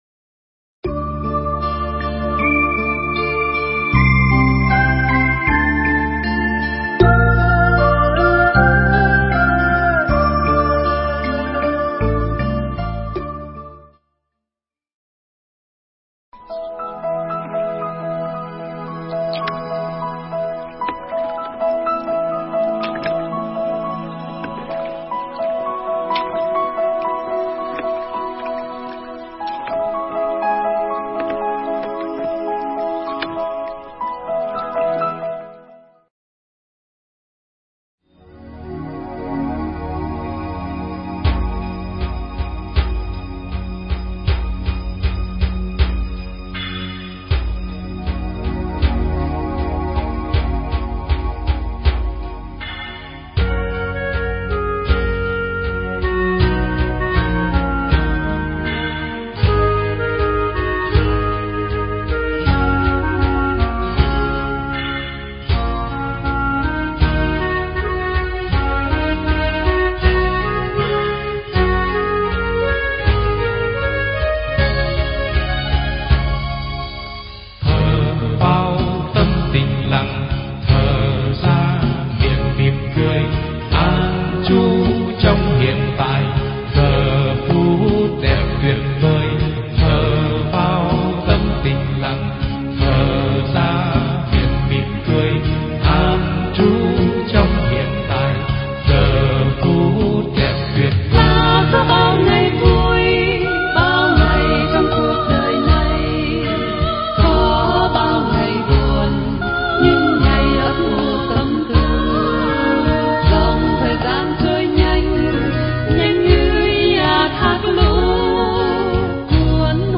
Nghe Mp3 thuyết pháp Mình Với Chính Mình Phần 2
Tải mp3 pháp thoại Mình Với Chính Mình Phần 2